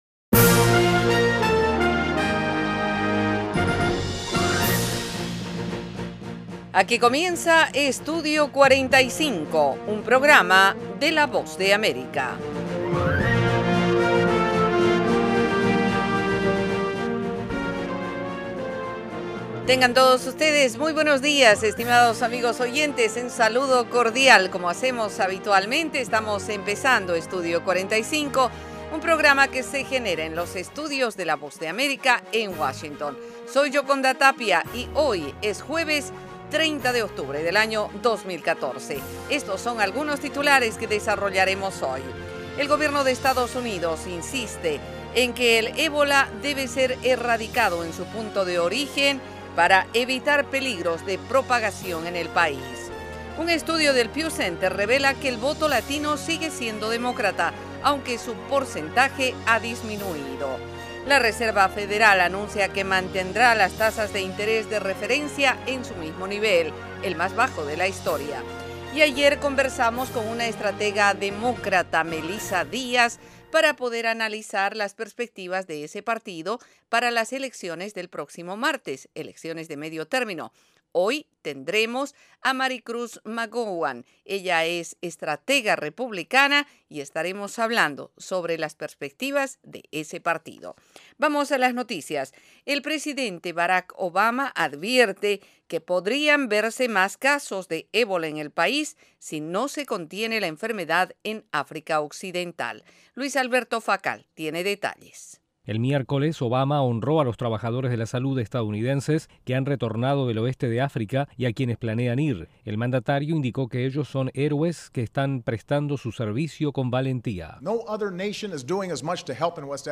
El programa ofrece -en 30 minutos- la actualidad noticiosa de Estados Unidos con el acontecer más relevante en América Latina y el resto del mundo.